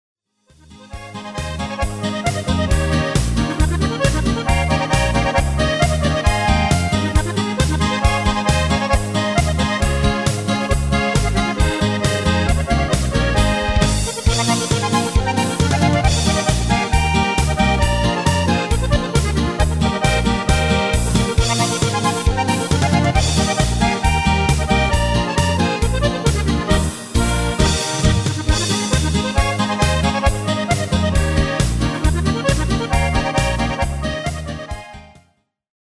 Demo/Koop midifile
Genre: Carnaval / Party / Apres Ski